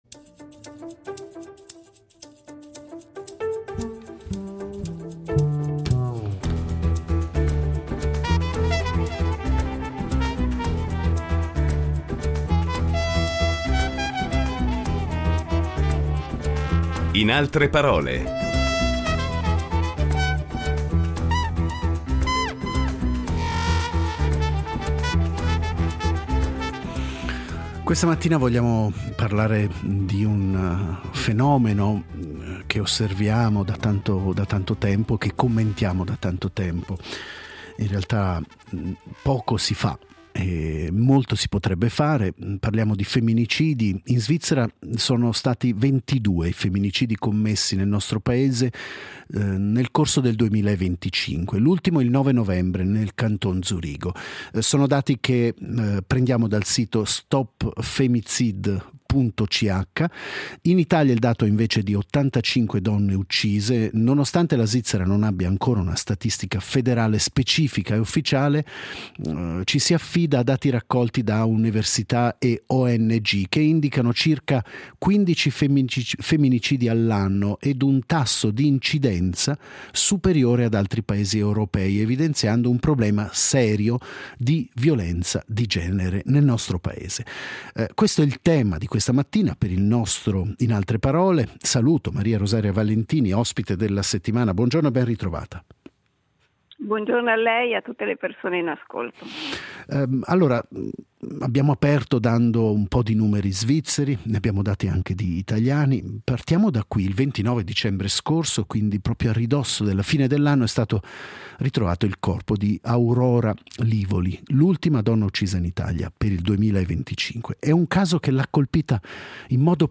Incontro con l’autrice che crede nel fuoco nascosto delle parole